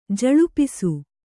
♪ jaḷupisu